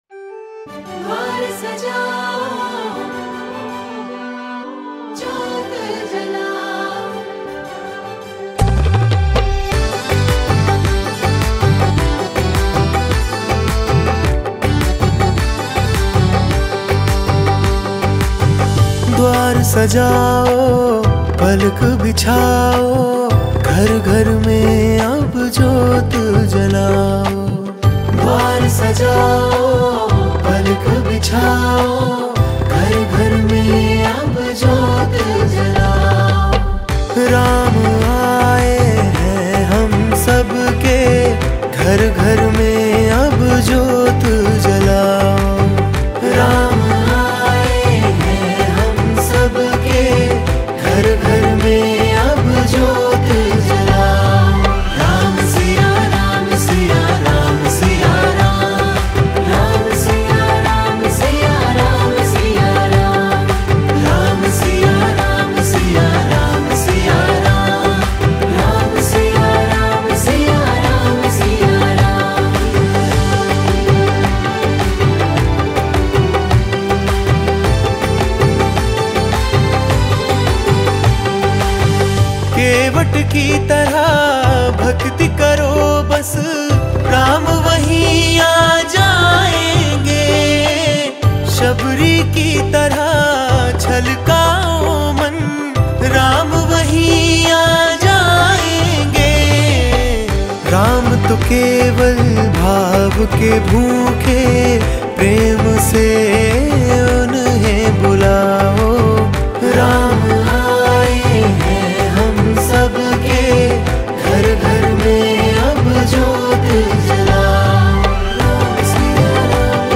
Releted Files Of Ram Ji Bhajan Mp3 Song